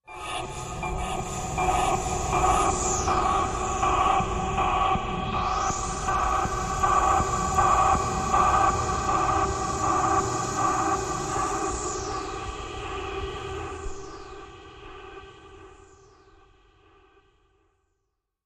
Processed Air Hiss
Pressure Release, Machine, Hollow, Air Release, Pressure